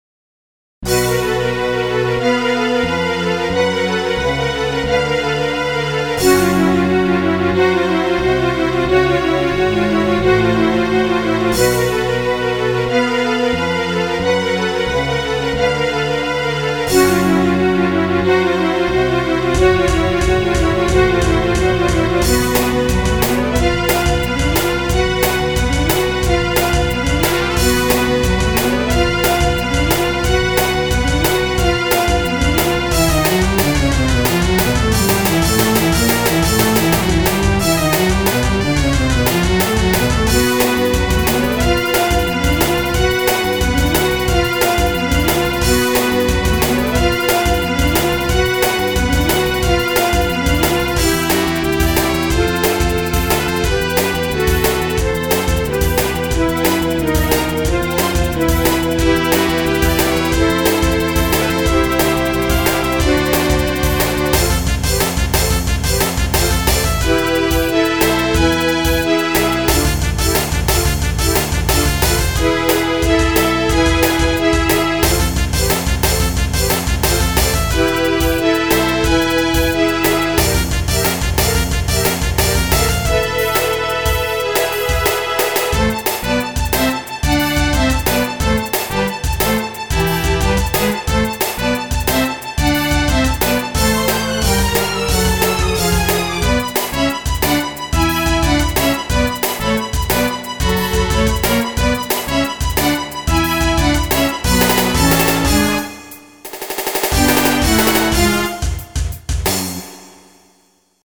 ストリングスの音を拾うのに苦労したので、音程は正確なはずです。